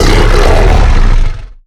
giant_aggressive_2.ogg